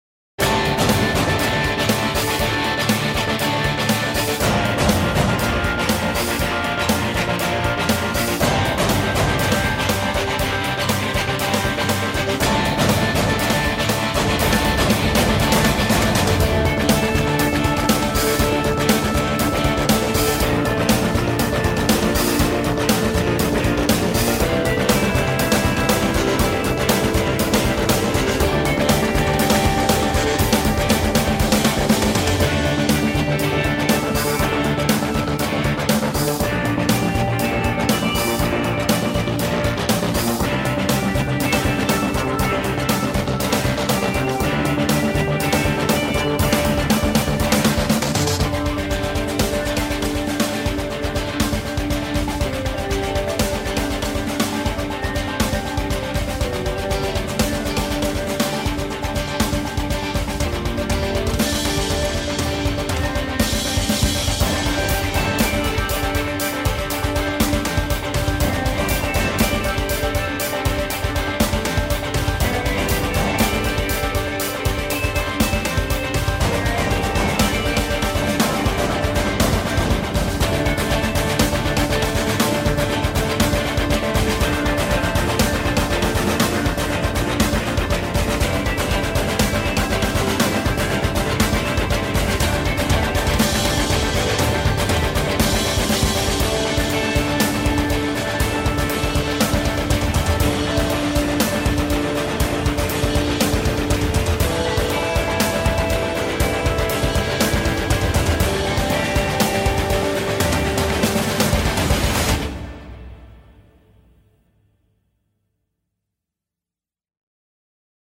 Upbeat track for racing and sports.
Upbeat track with guitars and drums for racing and sports.